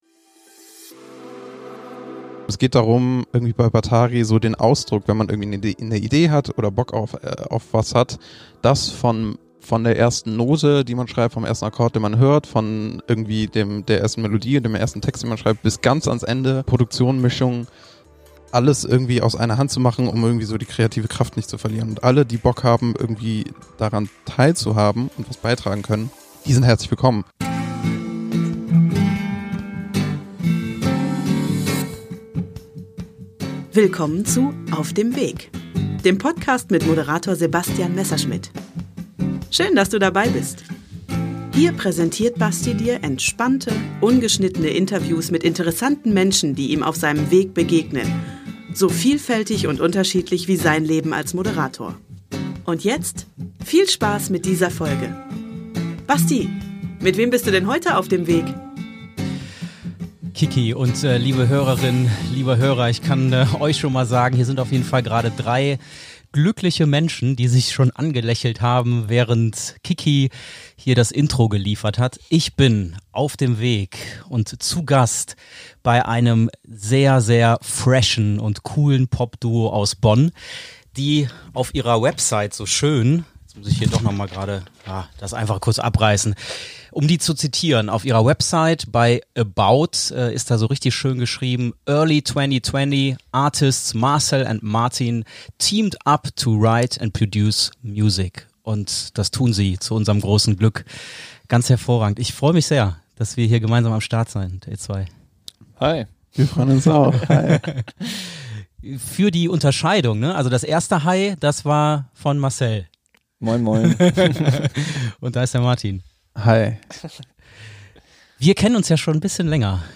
Wir hören in der Folge übrigens in beide Songs rein und sprechen an den Stellen detailliert über den jeweiligen Entstehungsprozess.